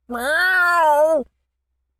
pgs/Assets/Audio/Animal_Impersonations/cat_scream_06.wav at master
cat_scream_06.wav